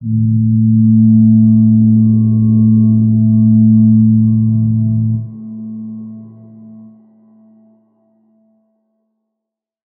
G_Crystal-A3-pp.wav